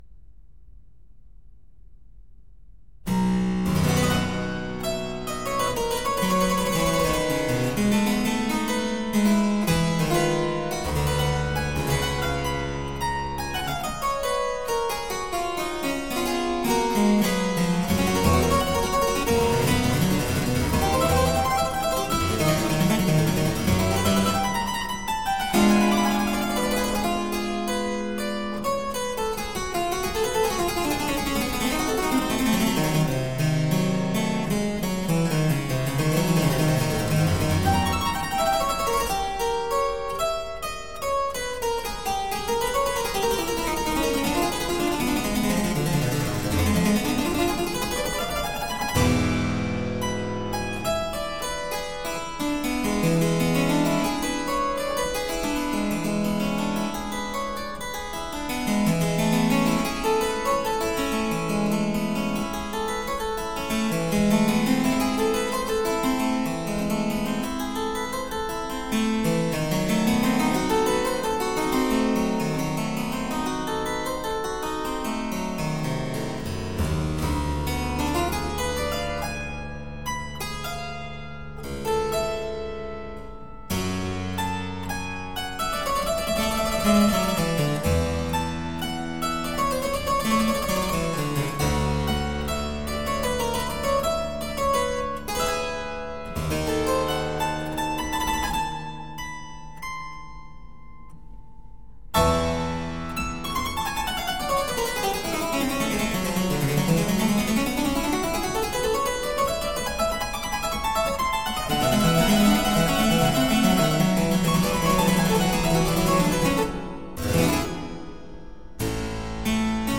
Group: Instrumental